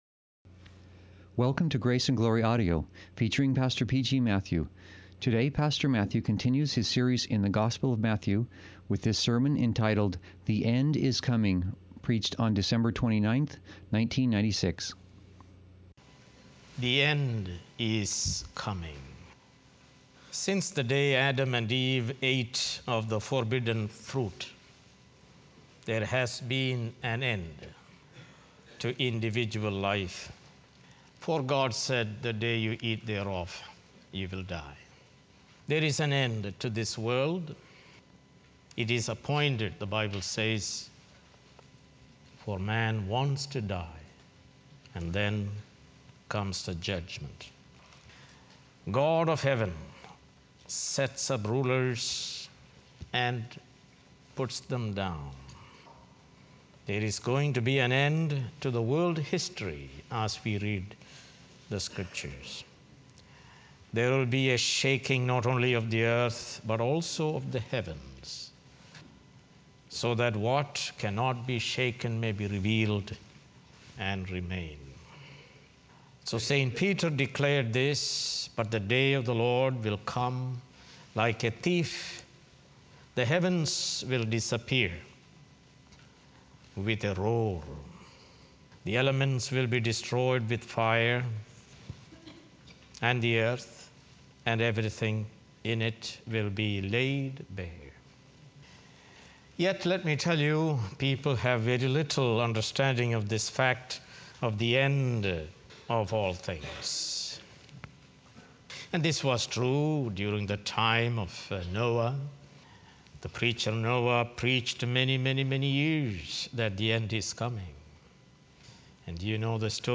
Sermons | Grace Valley Christian Center